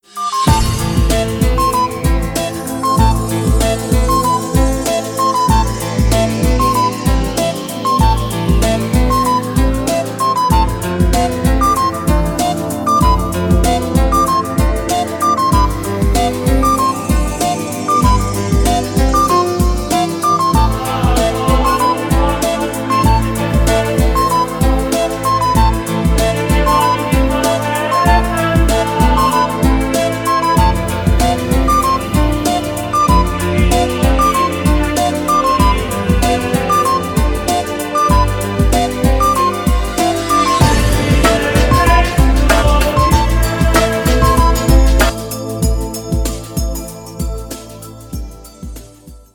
• Качество: 320, Stereo
красивые